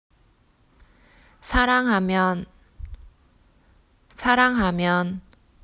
サランハミョン
さらんはみょん.mp3